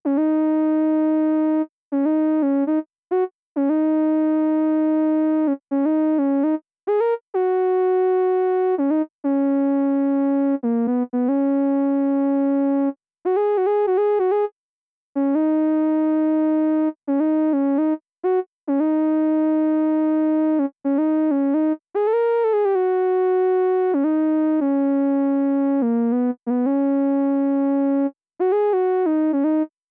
14 lead A2.wav